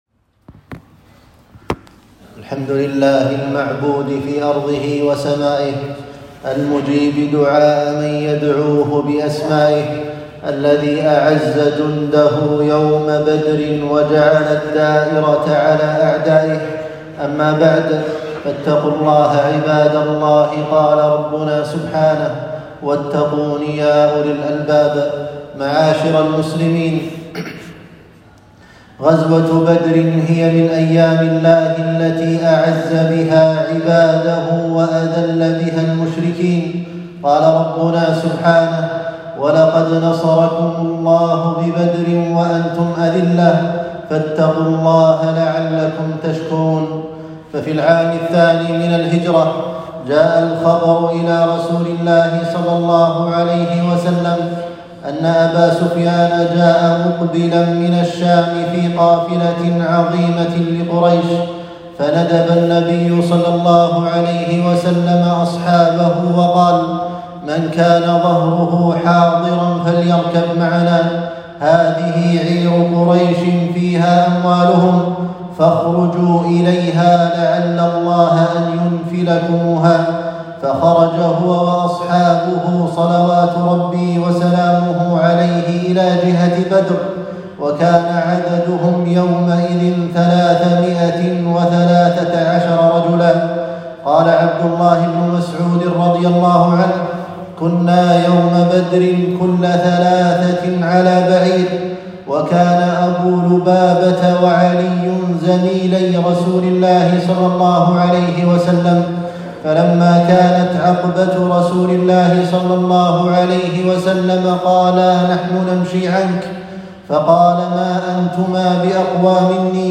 خطبة - غزوة بدر